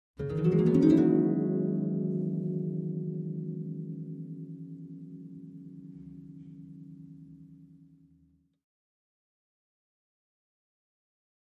Harp, Medium Strings Short Ascending Gliss, Type 2